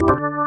键盘 " 风琴42
44khz 16位立体声，无波块。
Tag: 键盘 器官 DB33